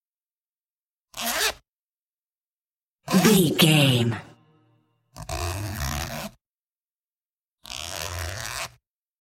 Zipper open close
Sound Effects